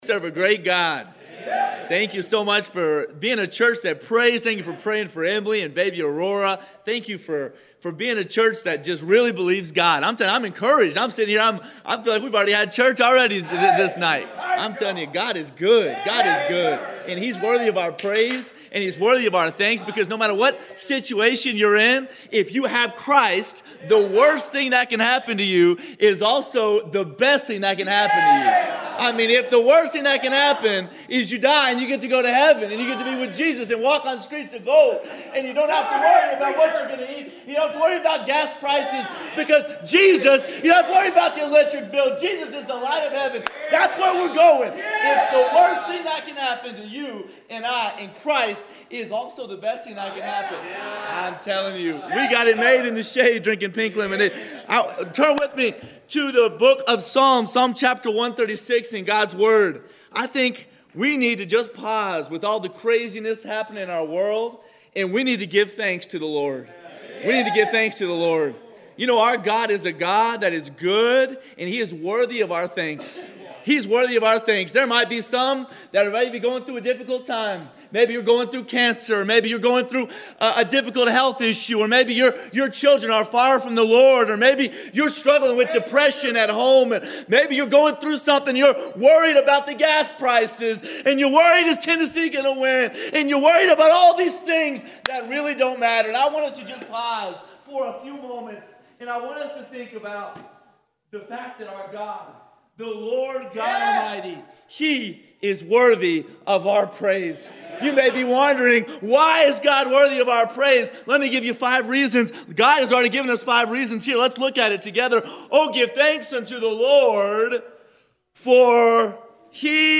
Passage: Psalm 136 Service Type: Sunday Evening « November 25,2025- Wed- If God Be For Us